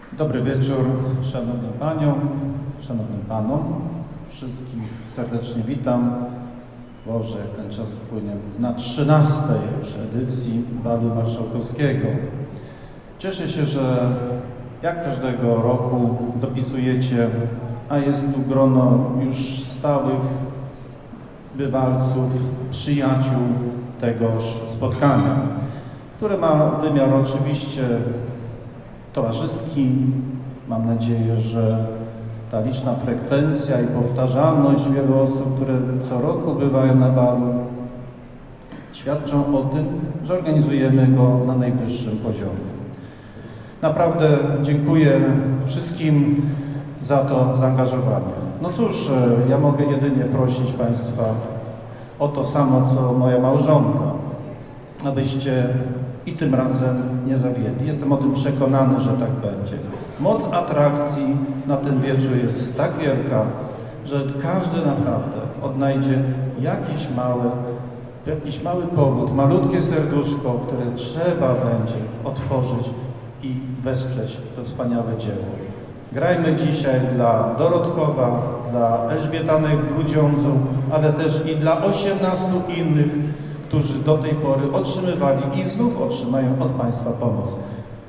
marszalek-Piotr-Calbecki-1.mp3